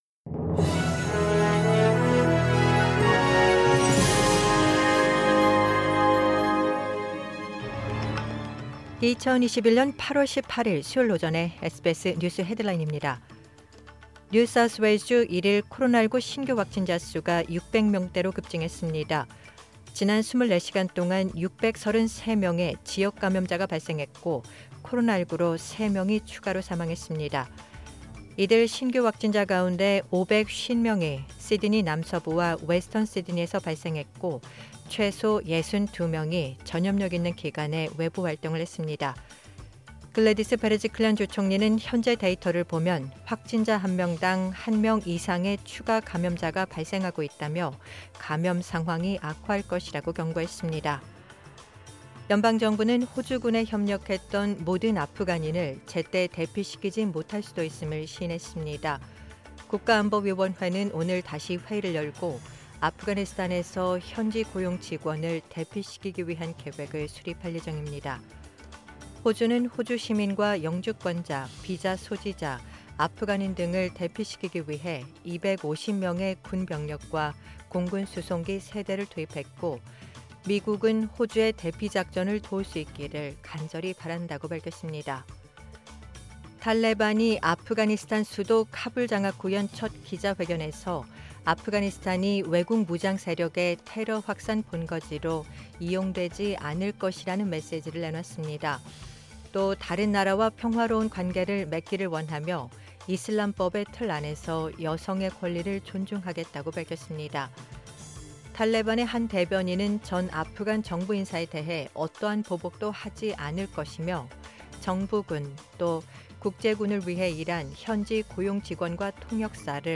2021년 8월 18일 수요일 오전의 SBS 뉴스 헤드라인입니다.